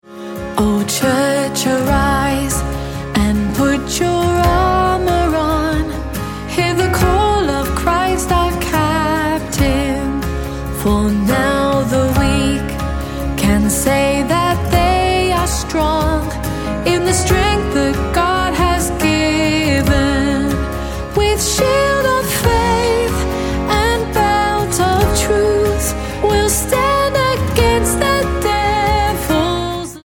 C#